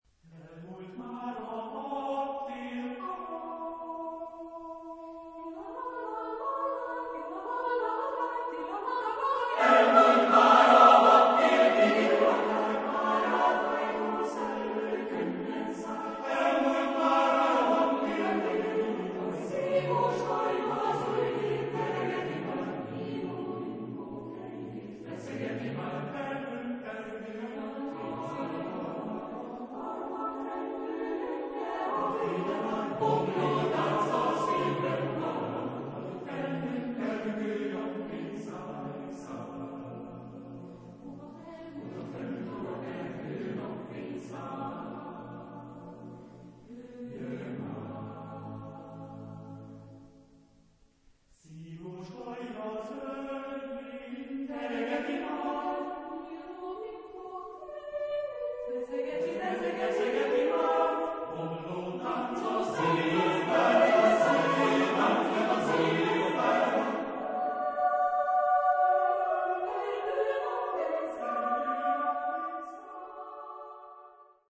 Genre-Style-Form: Popular ; Secular
Type of Choir: SATB  (4 mixed voices )